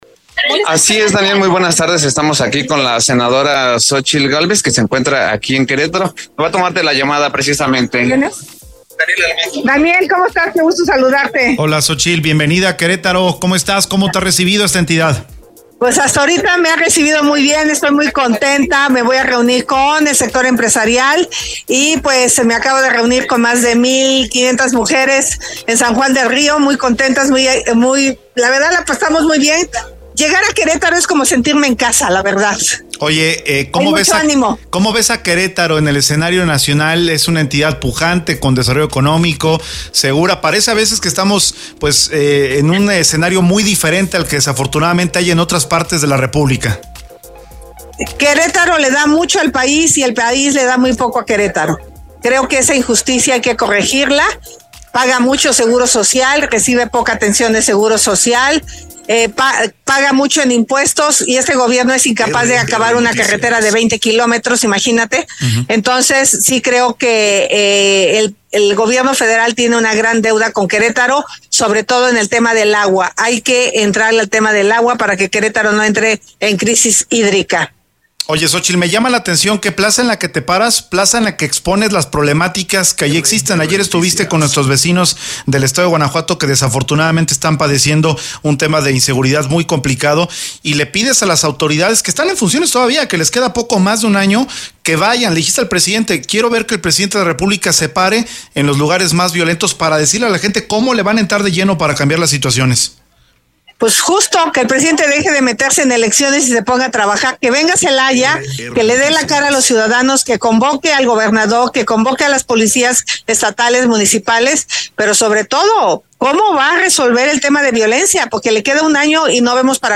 “Querétaro le ha dado mucho a México y México le ha quedado a deber a Querétaro”: Xóchitl Gálvez en entrevista exclusiva para RR Noticias